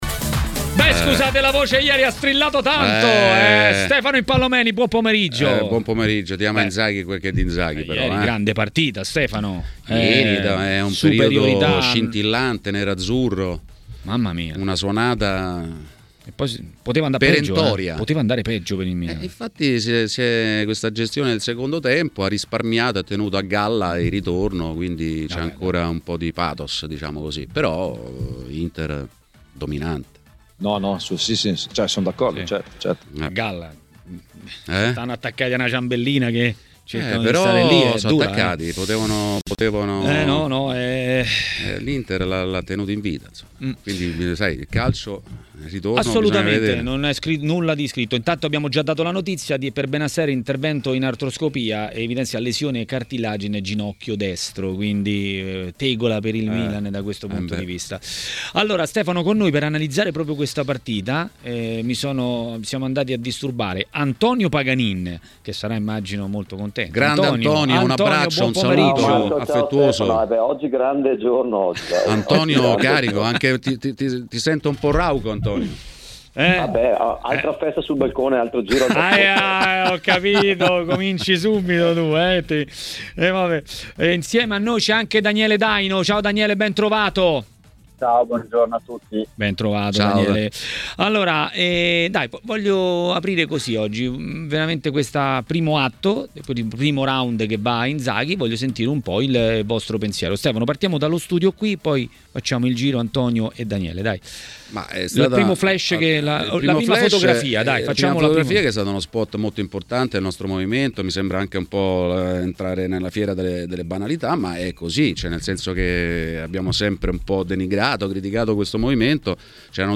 L'ex calciatore e tecnico Daniele Daino a TMW Radio, durante Maracanà, ha parlato dell'Euroderby vinto dall'Inter.